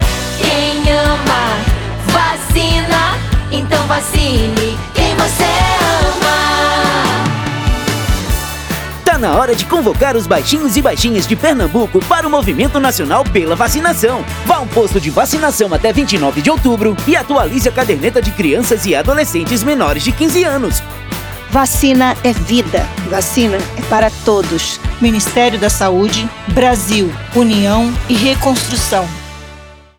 Áudio - Spot 30seg - Campanha de Multivacinação em Pernambuco - 1,1mb .mp3